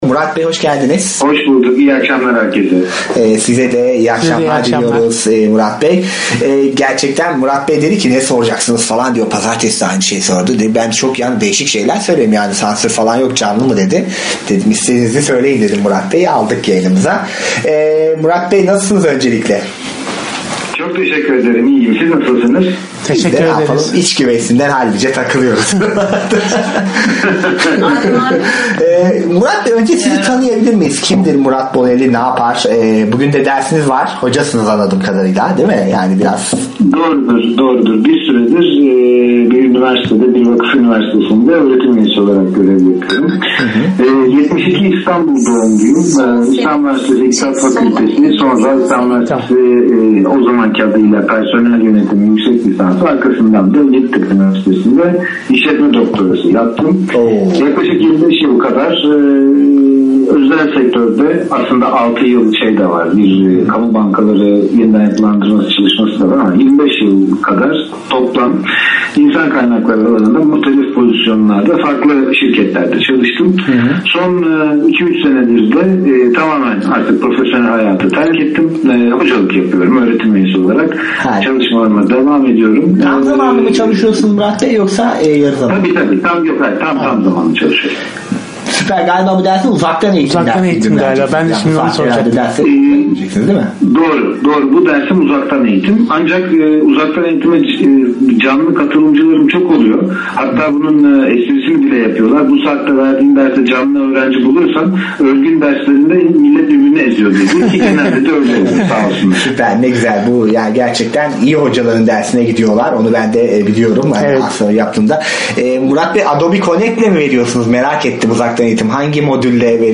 Gönüllü okuyucu röportajları